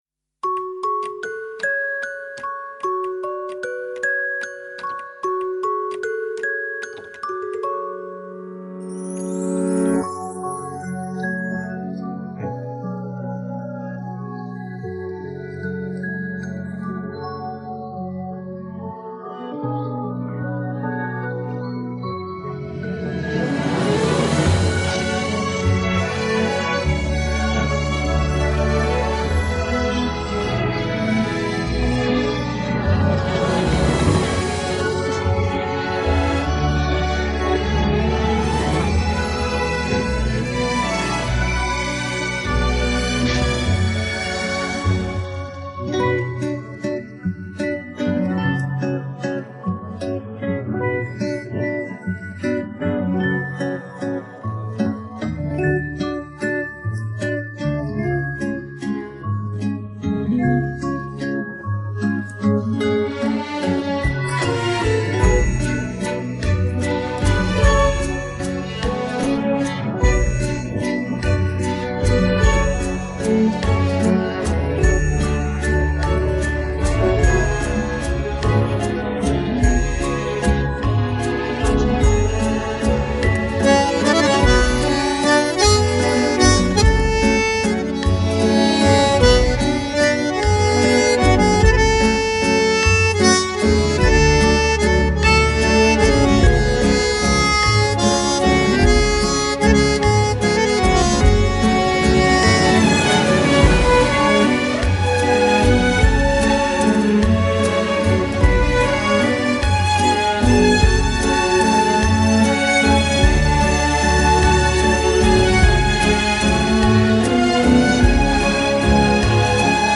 پخش نسخه بی‌کلام